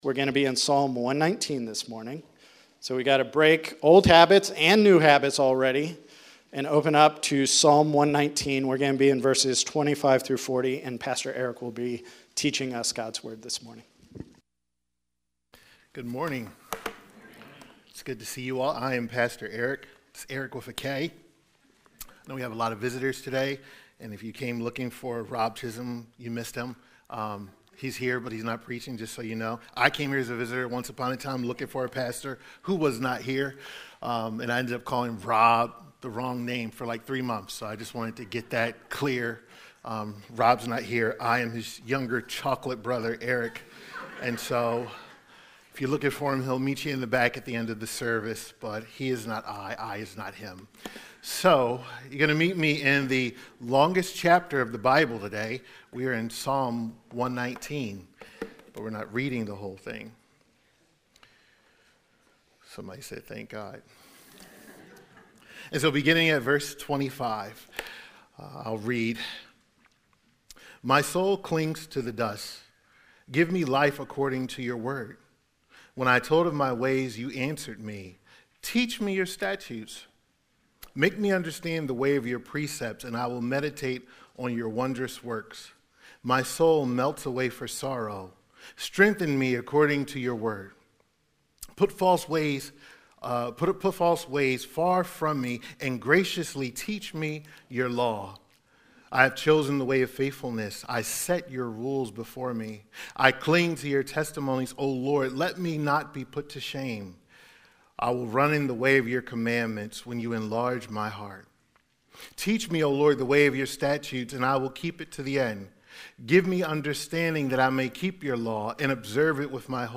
From Series: "New Year's Sermons"